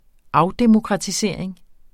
Udtale [ ˈɑwdemokʁɑtiˌseˀɐ̯eŋ ]